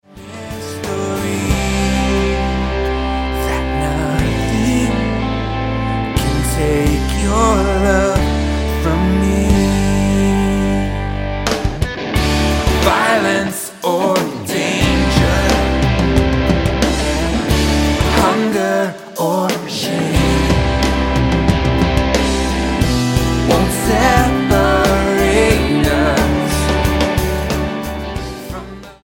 STYLE: Pop
Recorded live at St. Catherine's Church in Dublin, Ireland